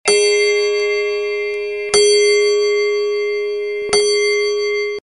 Тихий звук часового биения в домашних часах